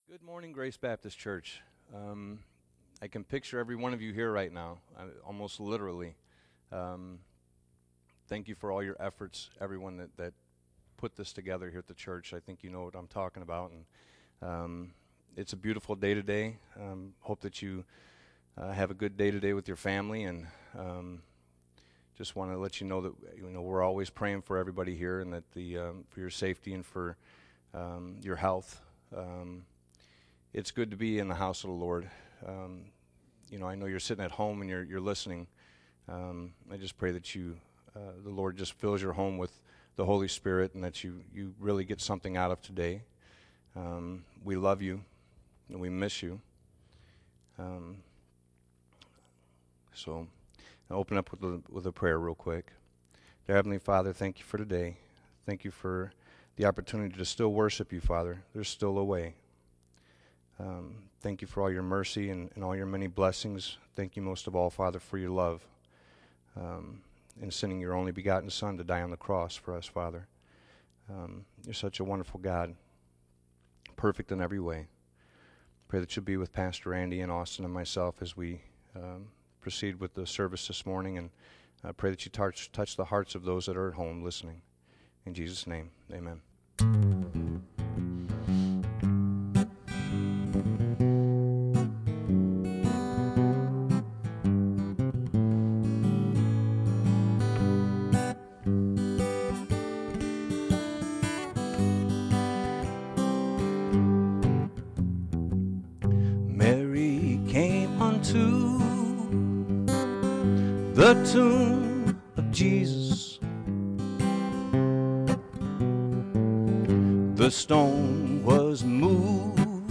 Sermons
sermons preached at Grace Baptist Church in Portage, IN